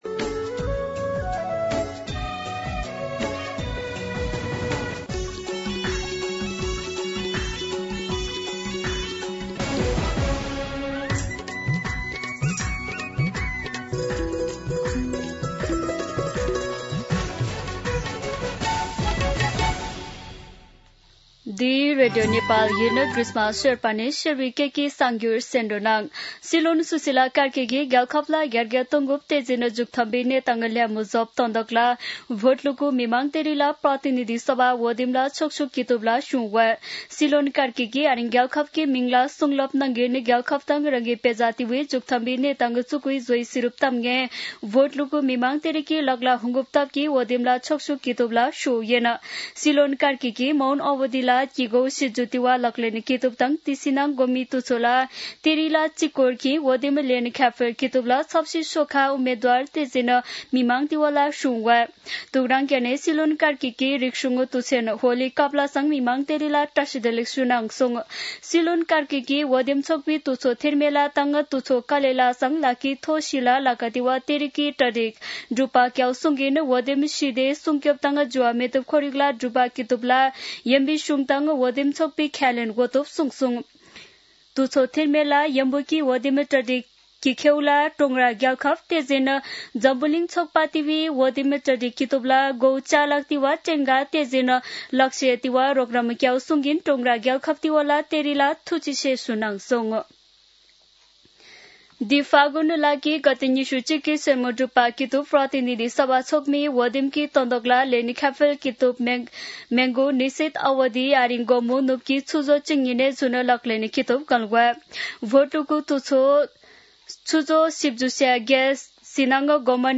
शेर्पा भाषाको समाचार : १८ फागुन , २०८२
Sherpa-News-18.mp3